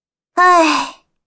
女生叹气音效_人物音效音效配乐_免费素材下载_提案神器
女生叹气音效免费音频素材下载